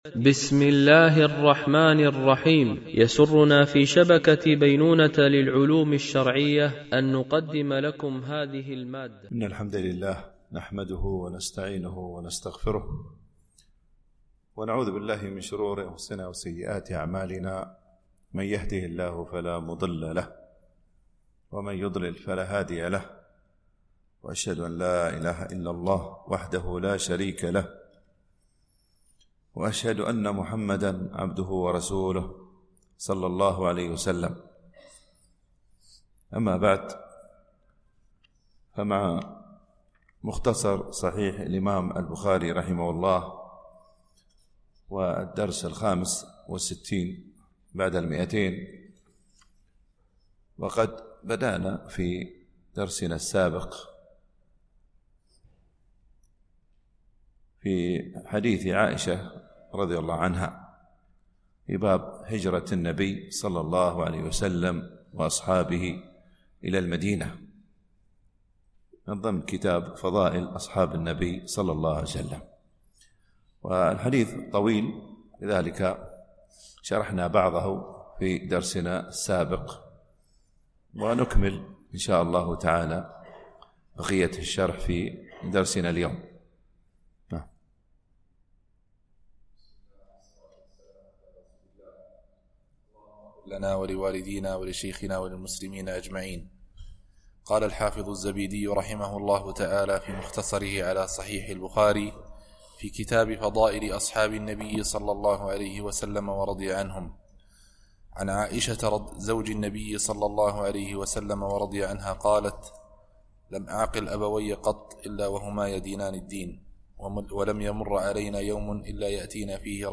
- الجزء السابع عشر- الحديث 1593) الألبوم: شبكة بينونة للعلوم الشرعية التتبع: 255 المدة: 53:37 دقائق (24.56 م.بايت) التنسيق: MP3 Mono 44kHz 64Kbps (VBR)